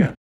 starloTalk.wav